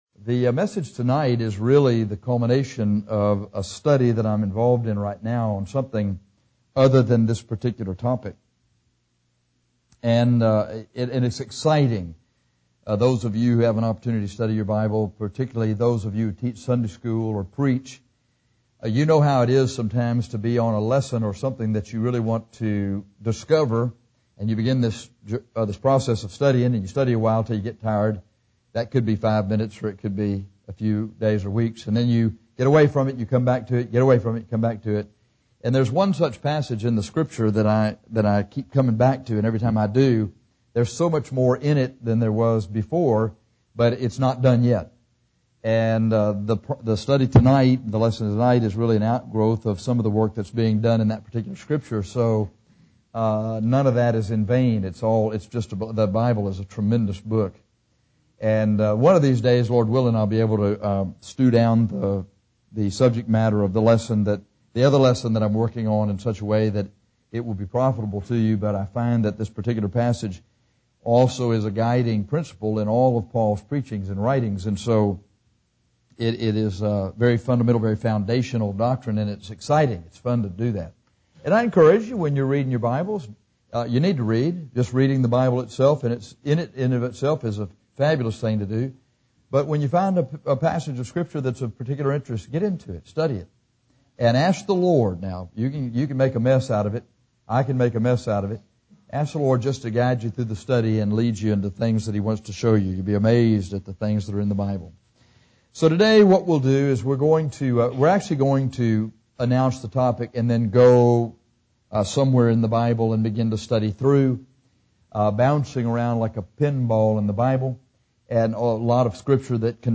Life Beyond the Grave is a sermon on the evidence that Old Testament saints had for life after death that corroborates the resurrection.